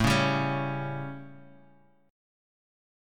A7 Chord